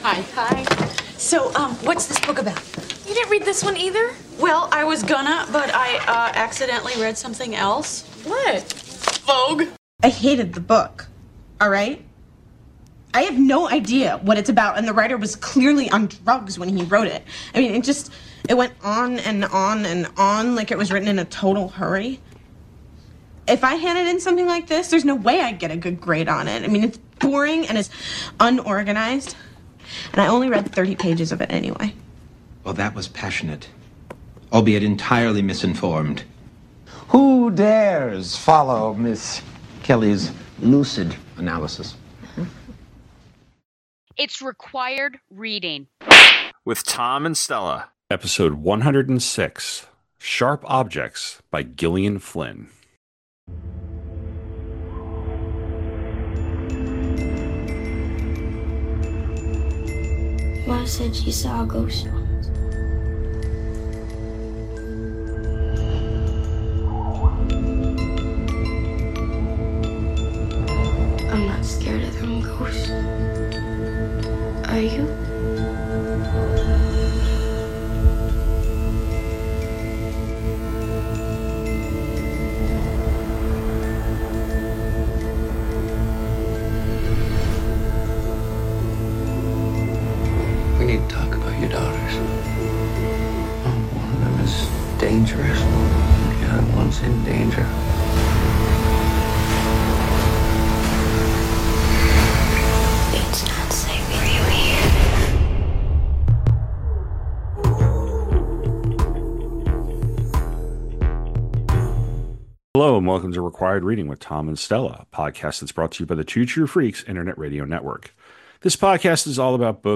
Each episode, we will be taking a look at a single work, analyzing it, criticizing it and deciding if it’s required reading.